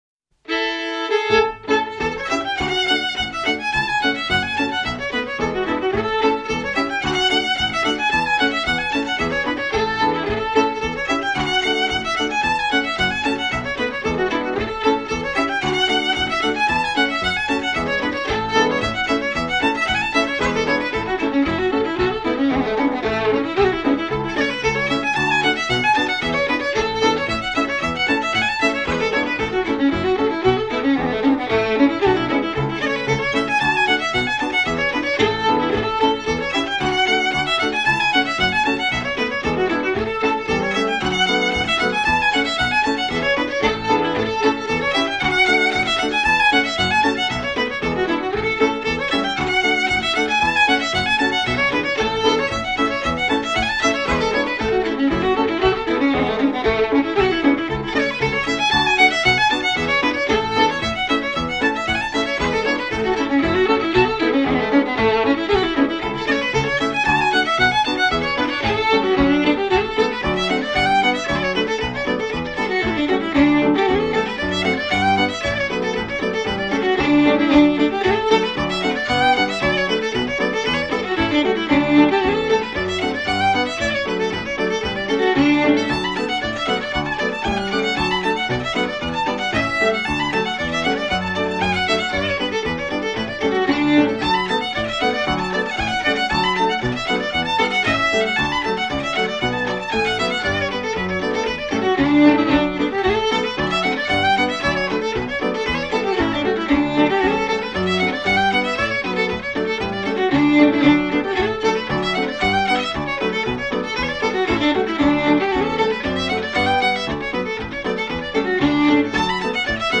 Cape Breton Reels